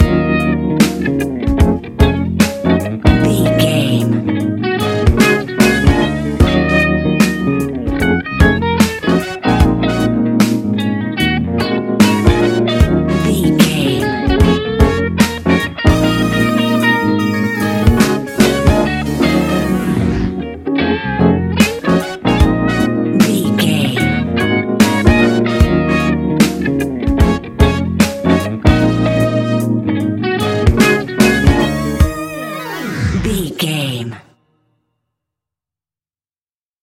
Epic / Action
Fast paced
In-crescendo
Uplifting
Ionian/Major
D♯